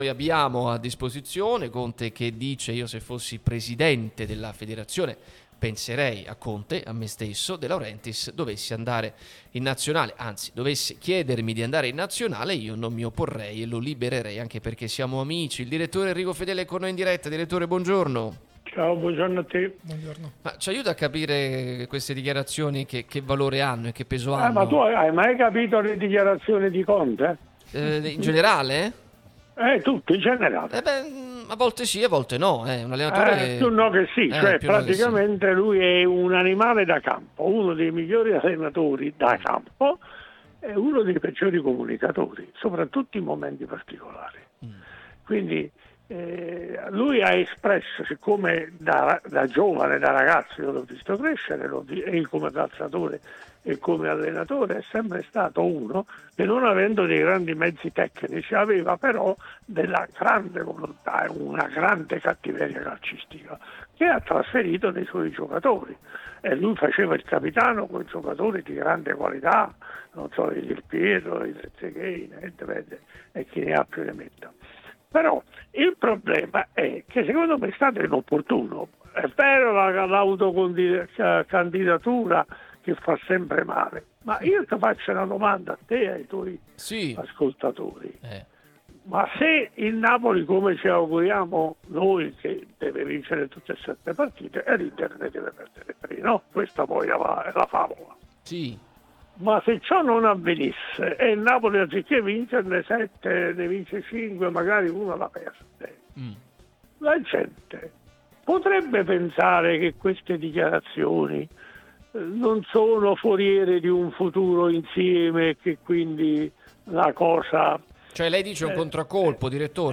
l'unica radio tutta azzurra e sempre live